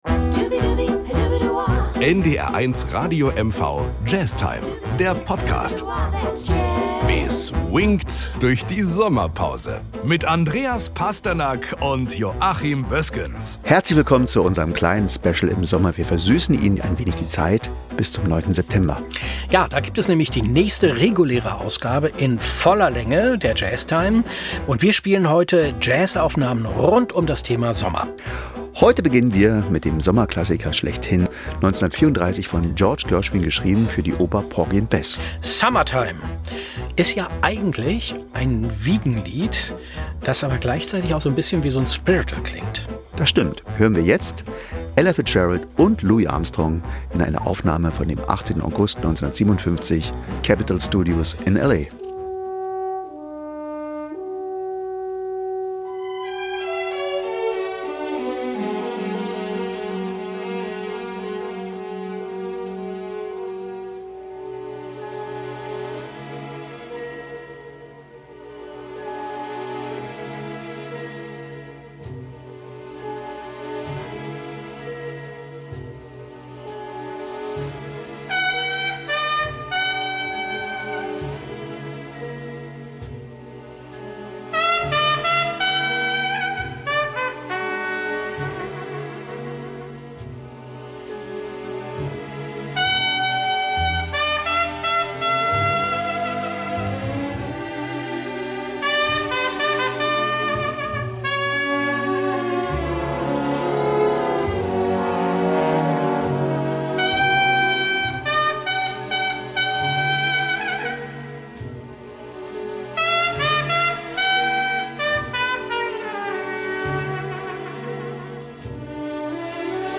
Nachrichten aus Mecklenburg-Vorpommern - 30.05.2025